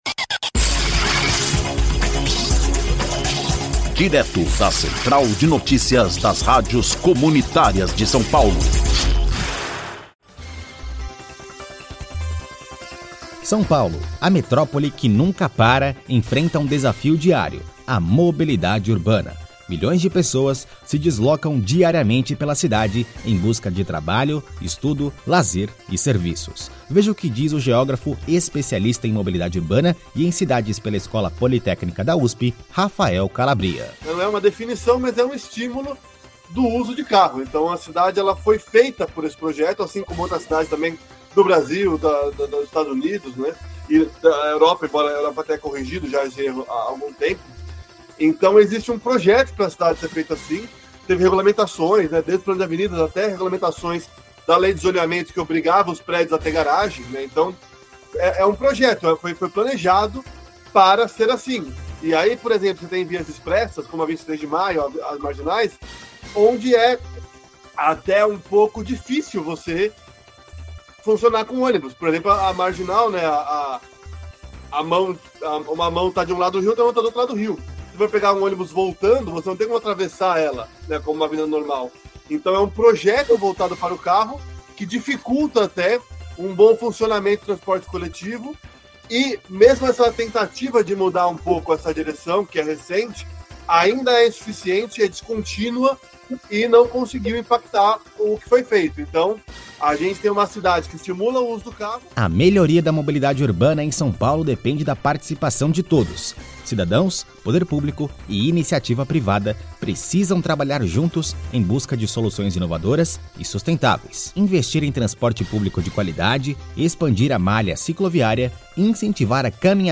INFORMATIVO: São Paulo: A busca por uma mobilidade urbana eficiente e acessível